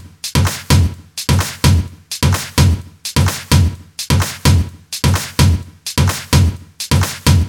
VDE 128BPM Notice Drums 7.wav